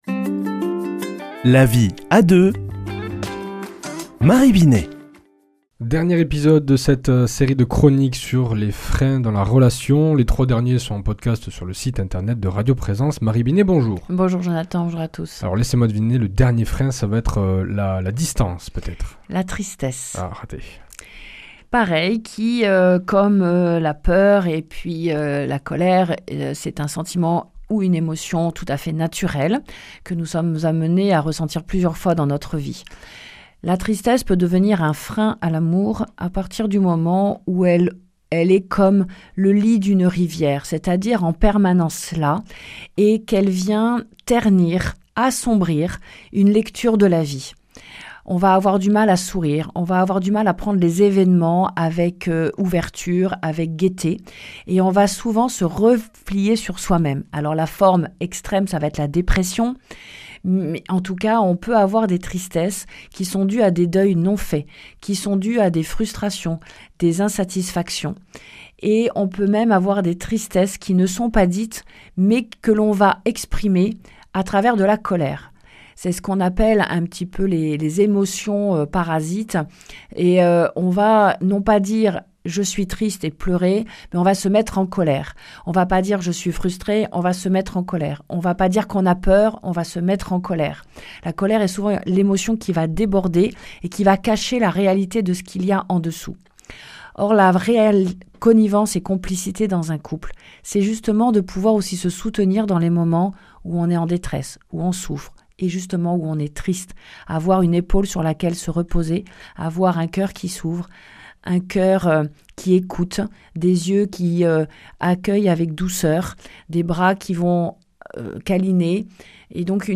mardi 11 novembre 2025 Chronique La vie à deux Durée 4 min
Une émission présentée par